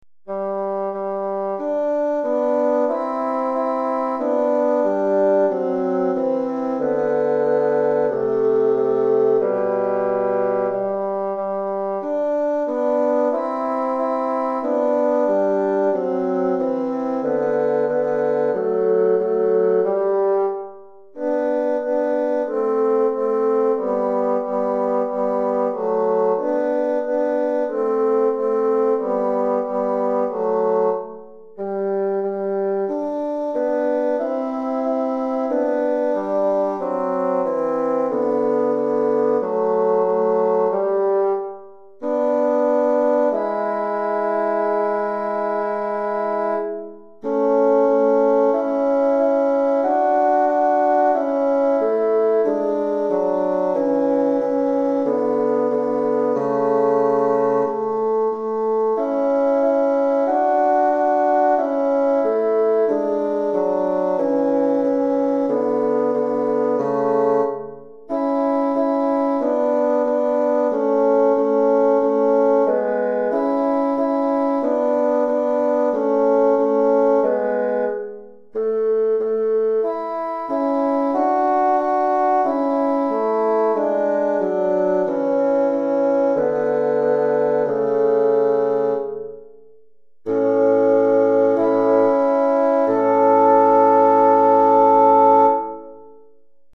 Recueil pour Basson - 2 Bassons